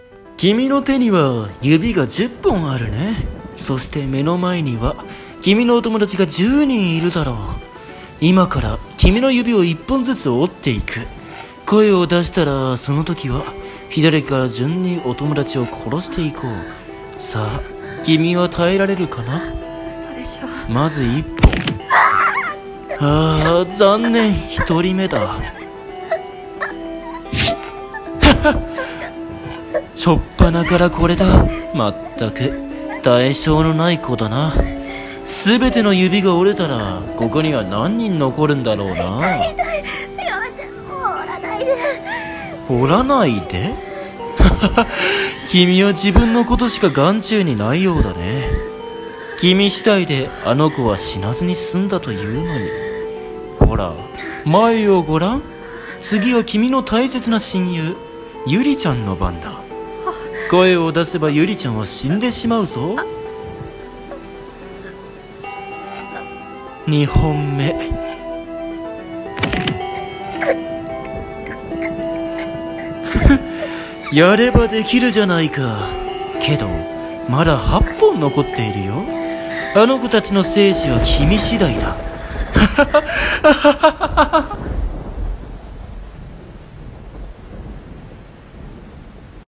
指折り殺人鬼【ホラー声劇